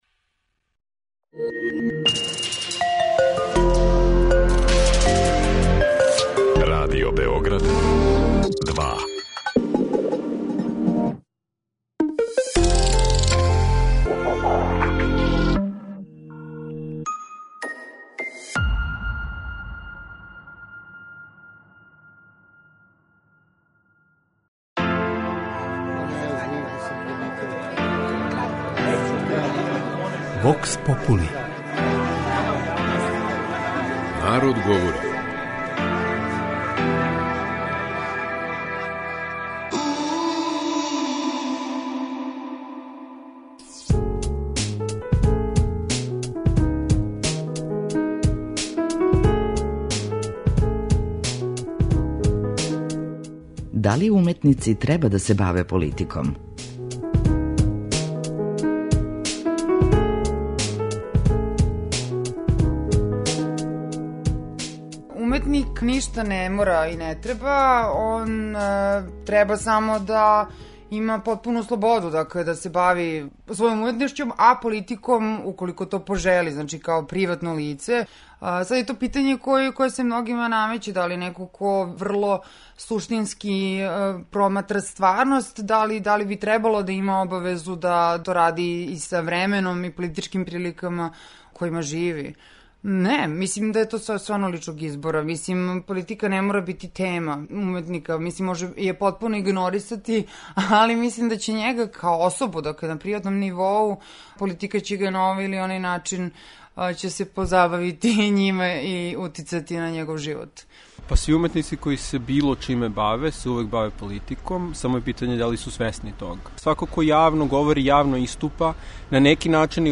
кроз кратке монологе, анкете и говорне сегменте